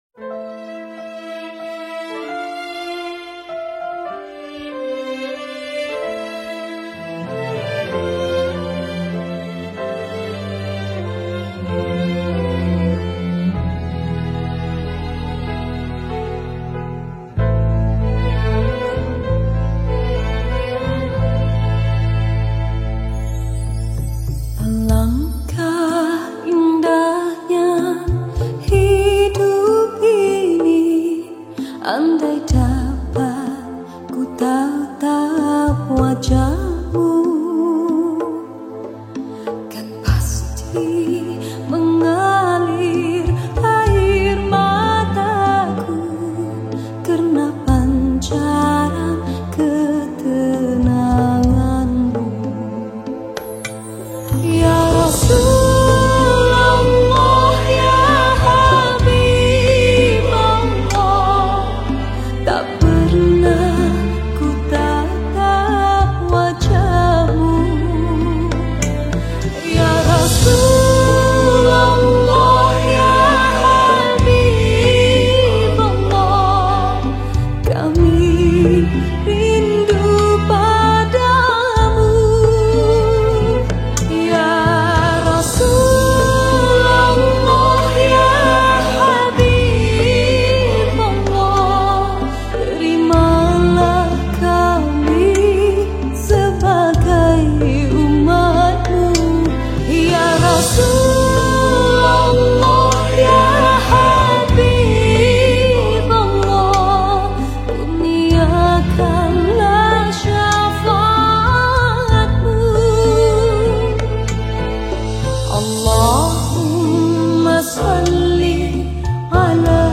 Arabic Songs , Nasyid Songs
Lagu Nasyid